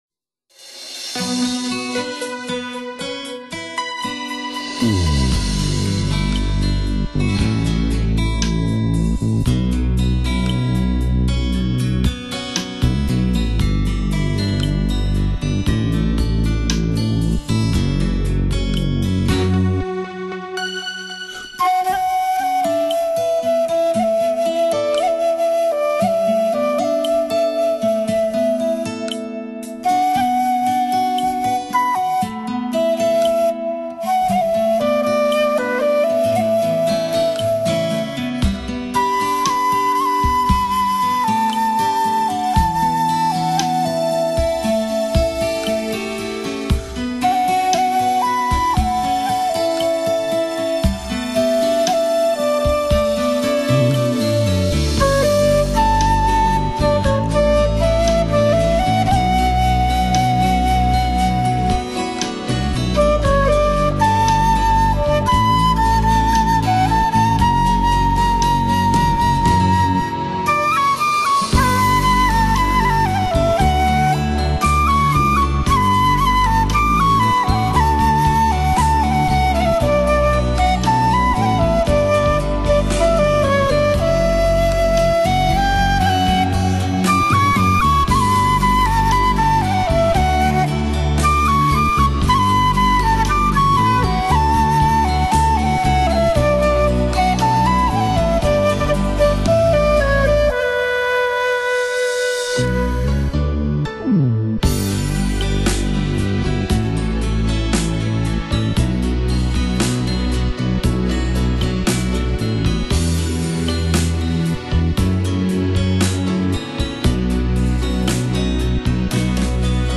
箫独奏发烧专辑
箫是一种独特的民族乐器，以其低沉迂回的音色，往往引起许多莫名的愁绪来。
烁金的岁月给爱情镶了一条晦涩的圈，在一往情深的萧声执着中融化了沉默。。。